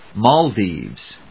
音節Mal・dives 発音記号・読み方
/mˈɔːldiːvz(米国英語), ˈmɒl.diːvz(英国英語)/